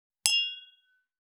335ガラスのグラス,ウイスキー,コップ,食器,テーブル,チーン,カラン,キン,コーン,チリリン,カチン,チャリーン,クラン,カチャン,クリン,シャリン,チキン,コチン,カチコチ,チリチリ,シャキン,
コップ